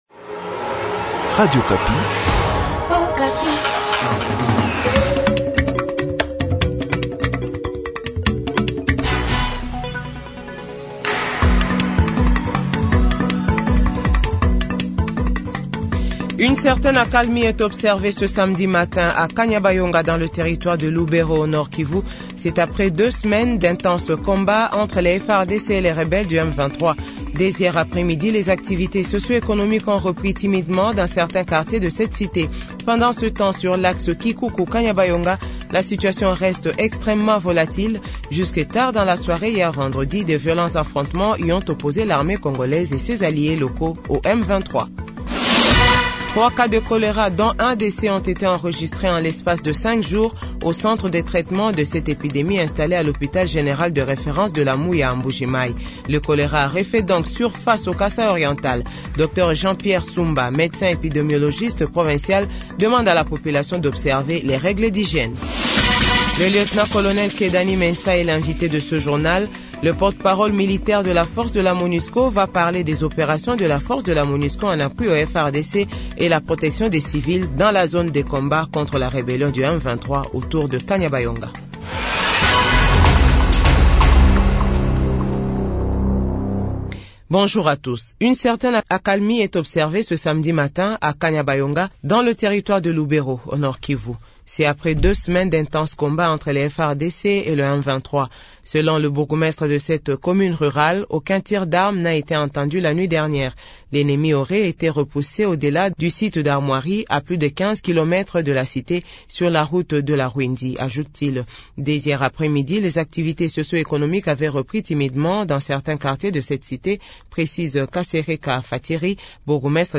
JOURNAL FRANÇAIS 12H00